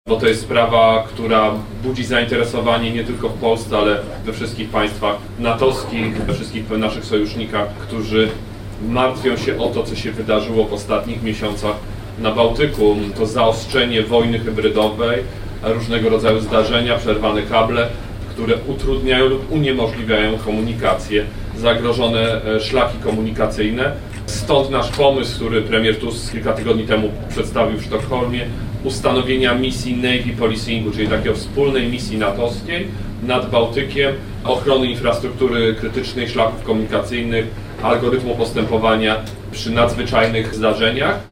– mówił lider PSL na zwołanej wcześniej konferencji prasowej w Szczecinie.